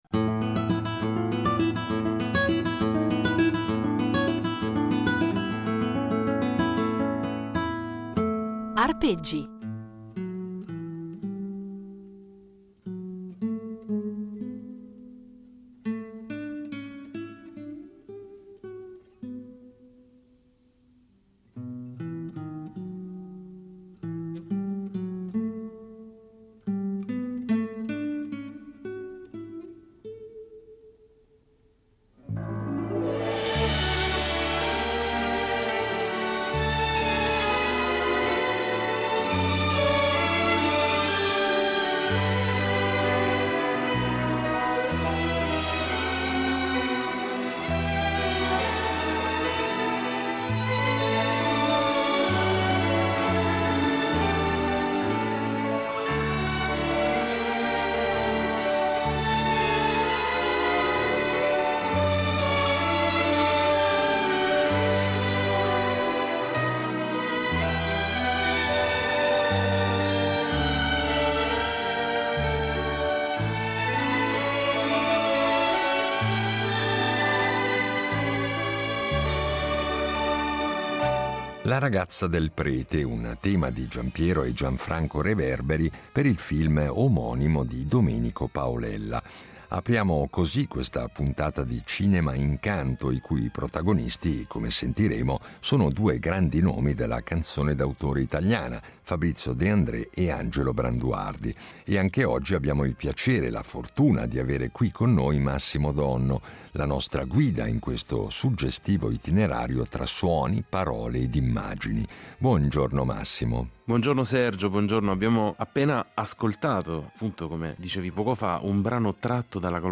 Insieme, andranno quindi ad impreziosire i nostri itinerari sonori con esecuzioni inedite di canzoni che hanno contribuito a rendere indimenticabili alcune pellicole cinematografiche.